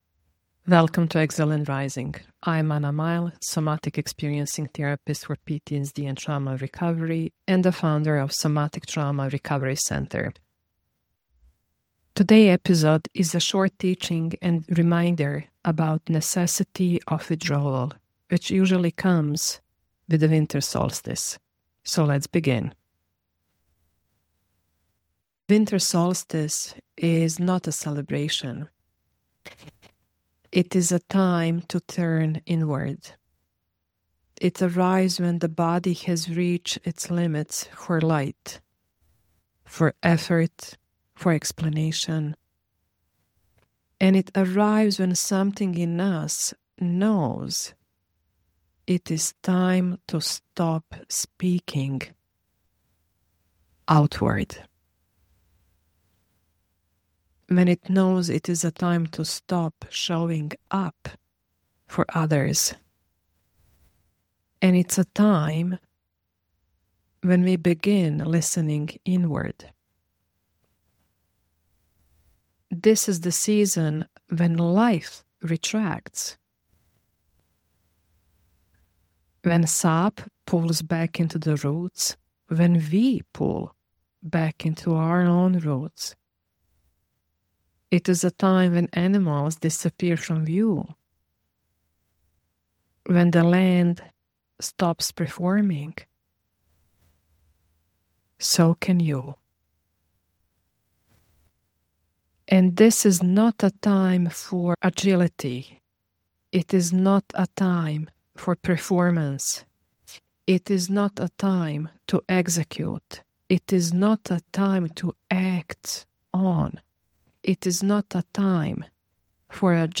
Why Withdrawal Is Necessary for Regulation : Winter Solstice Teachings